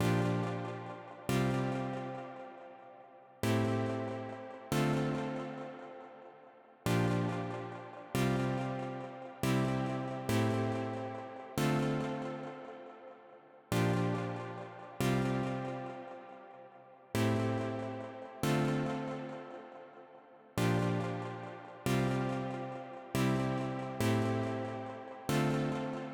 最初に選んだ音は全体を支えるイメージで選びましたので、次はコード音を強調する為に割とハッキリとした音を同じくAvengerから選びました。